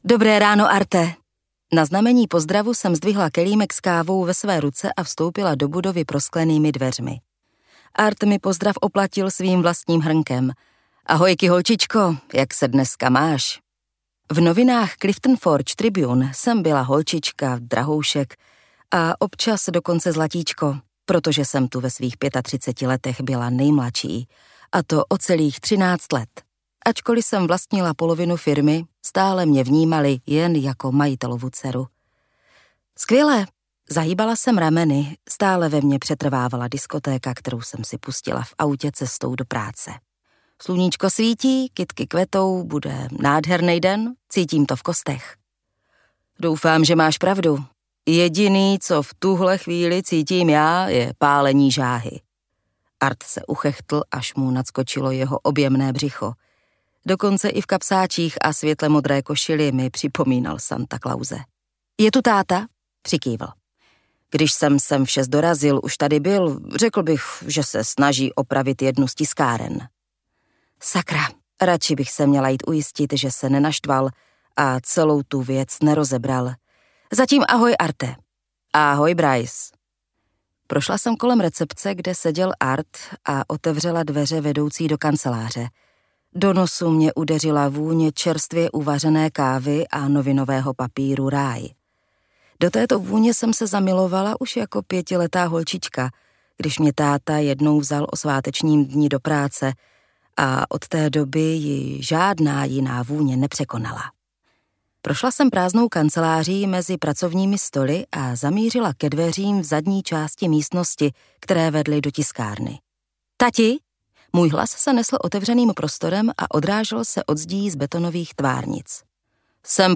MP3 Audiobook